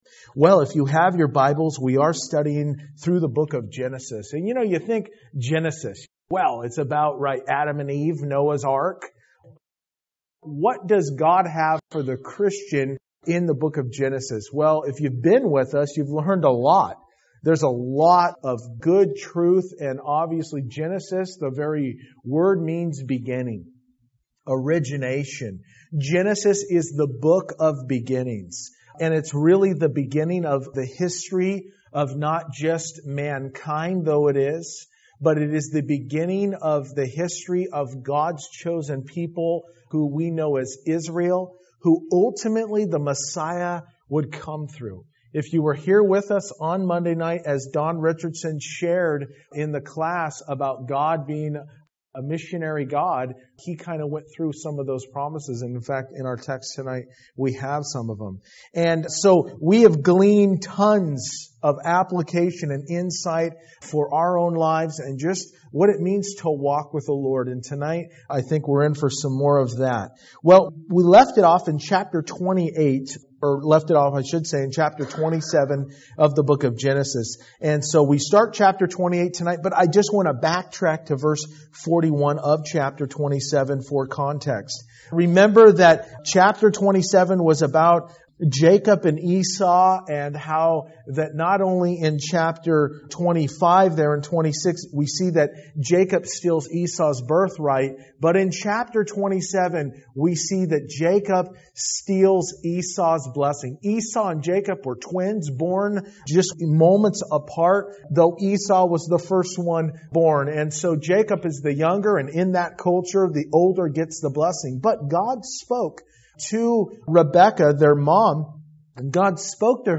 Verse by Verse-In Depth